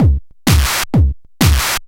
DS 128-BPM A6.wav